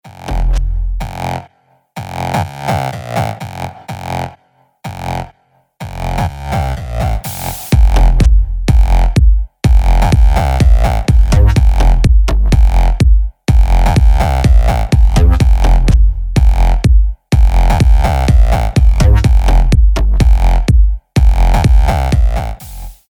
минимал
техно